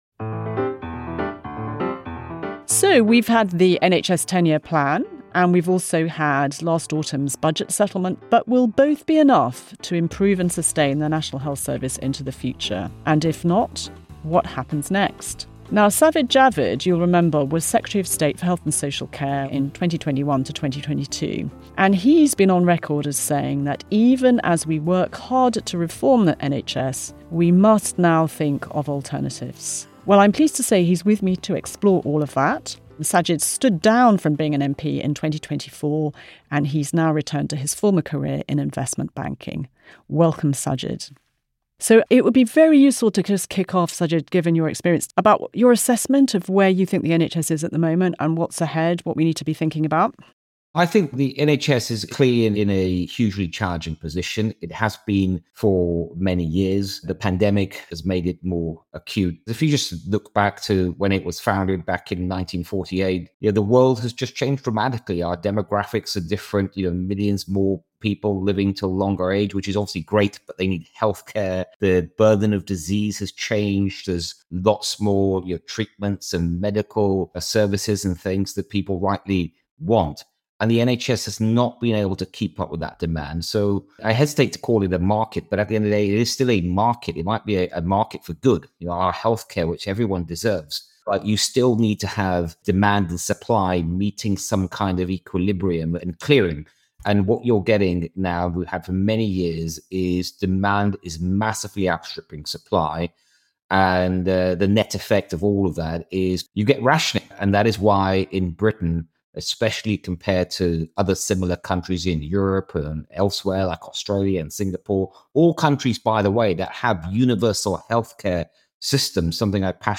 In our third conversation with former health secretaries, we speak to Sajid Javid about the future of the NHS and its long-term funding model.